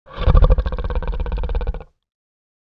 creature-sound